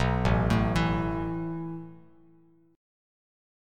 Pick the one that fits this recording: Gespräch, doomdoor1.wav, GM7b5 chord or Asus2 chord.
Asus2 chord